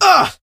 fang_hurt_vo_07.ogg